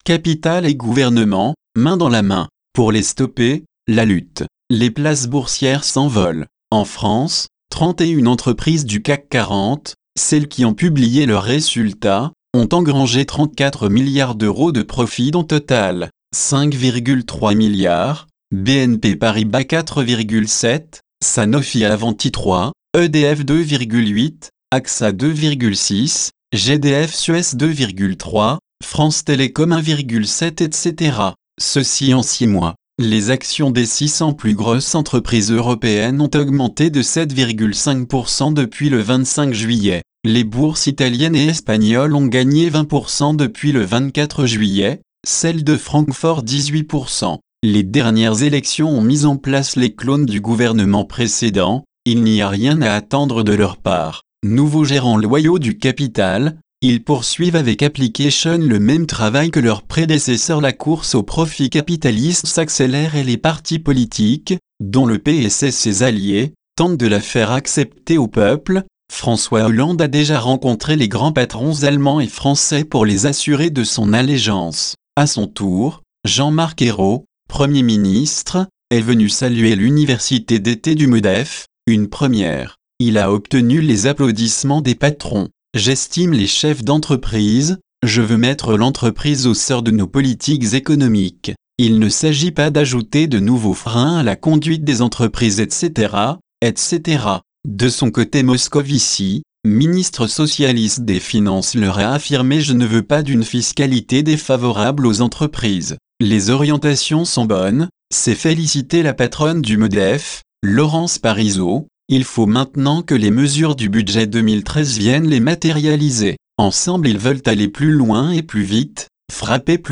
Ecouter la lecture de ce tract